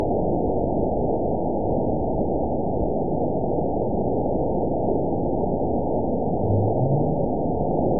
event 920419 date 03/24/24 time 00:25:34 GMT (1 year, 1 month ago) score 9.38 location TSS-AB02 detected by nrw target species NRW annotations +NRW Spectrogram: Frequency (kHz) vs. Time (s) audio not available .wav